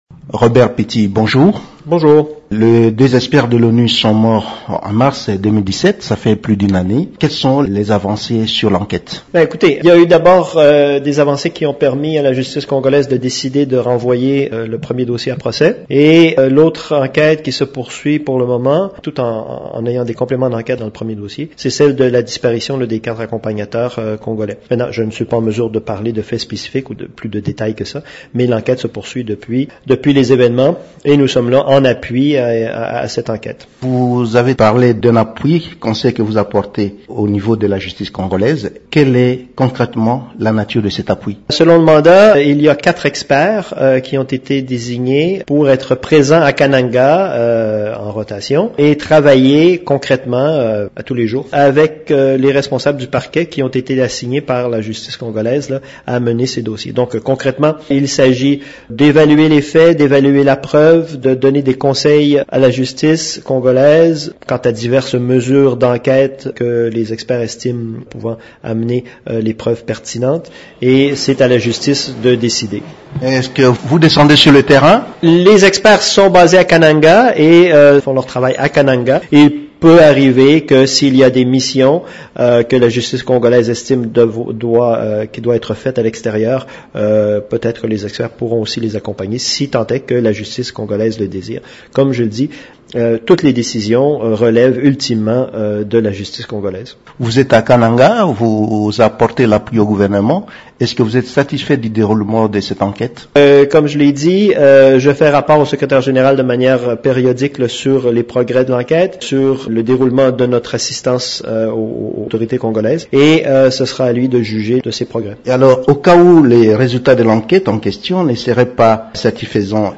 « C’est un mandat d’appui conseil à la justice congolaise qui est la seule, qui a l’autorité légale et la responsabilité de mener à bien la recherche de la vérité, les enquêtes et les procès dans ce meurtre », a expliqué Robert Petit, invité à la conférence bimensuelle des Nations unies.